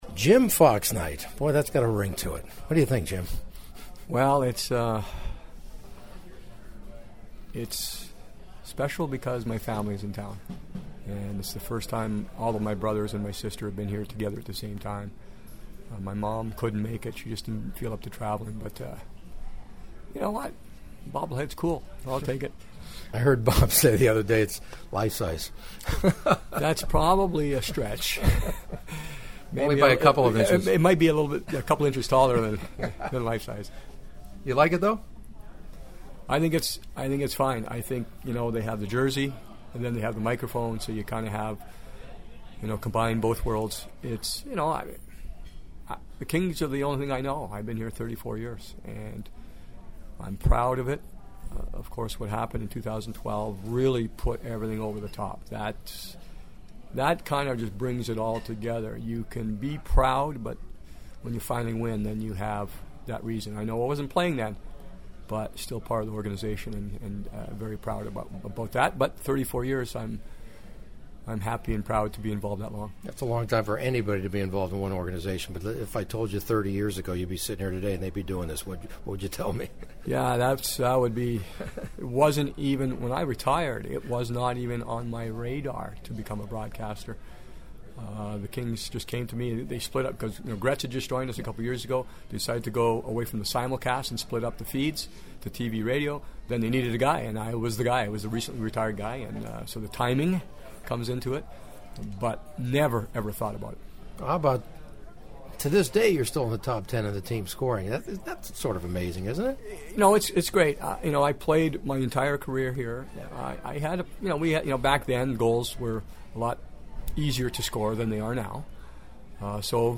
Jimmy is even a better hockey analyst alongside his TV partner Bob Miller and he was his usual kind self to give me a few minutes before tonight’s game with the Toronto Maple Leafs on Jim Fox Legend’s Night at Staples Center (which includes a bobblehead doll that he says might even be few inches taller than the real ‘Foxy’.